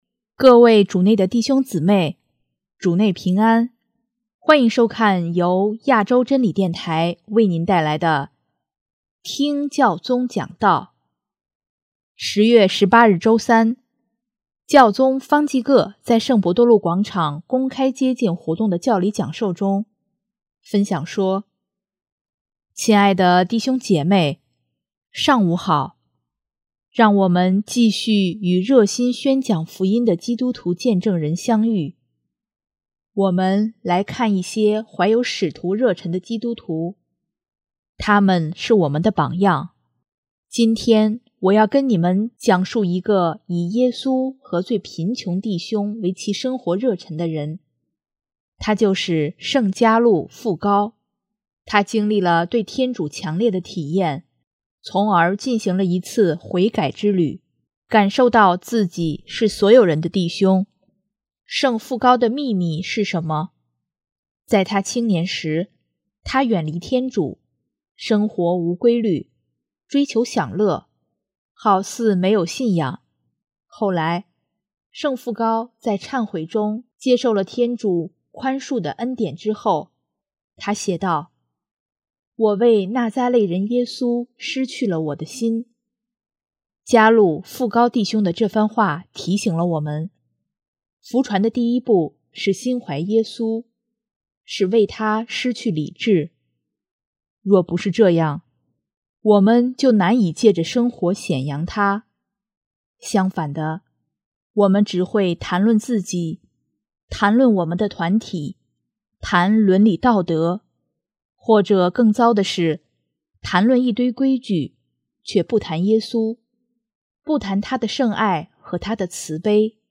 10月18日周三，教宗方济各在圣伯多禄广场公开接见活动的教理讲授中，分享说：